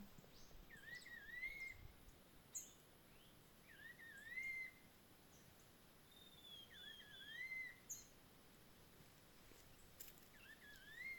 Juan Chiviro (Cyclarhis gujanensis)
Nombre en inglés: Rufous-browed Peppershrike
Provincia / Departamento: Misiones
Condición: Silvestre
Certeza: Vocalización Grabada